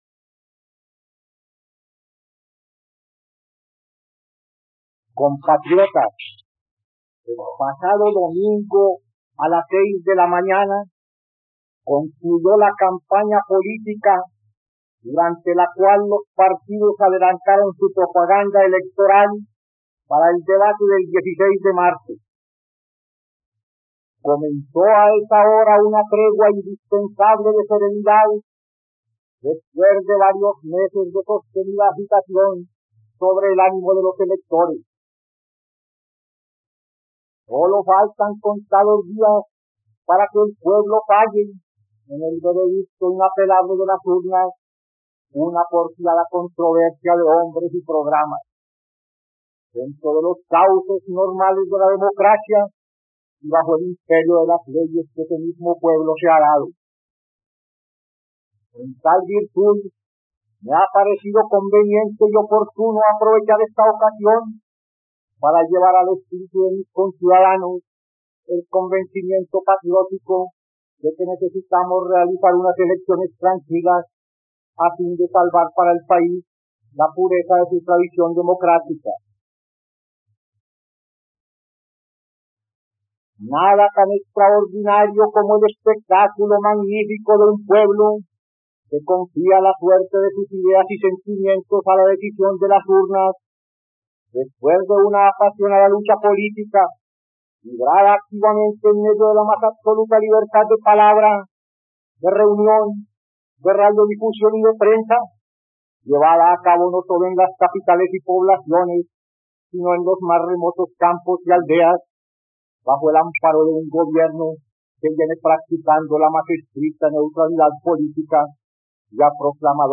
..Escucha ahora el discurso del presidente Mariano Ospina Pérez sobre las elecciones y la economía colombiana, el 12 de marzo de 1947, en RTVCPlay.